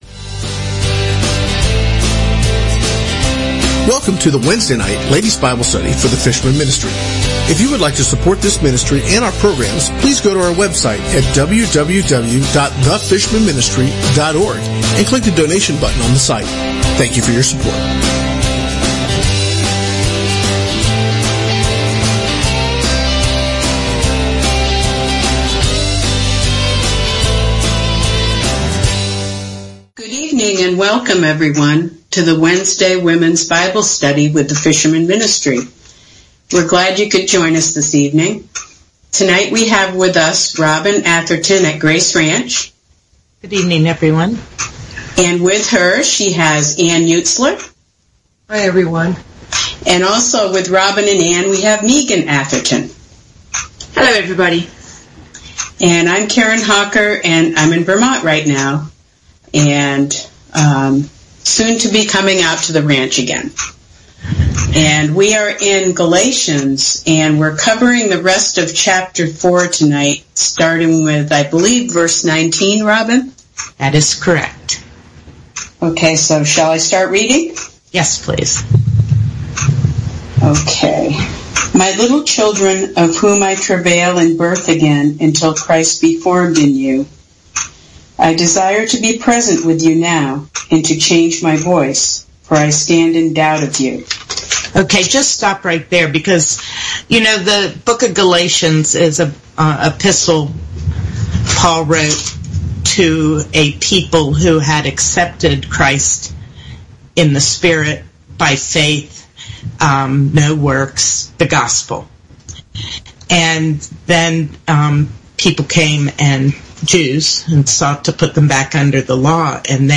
Wednesday Womens Bible Study 05/18/2016 | The Fishermen Ministry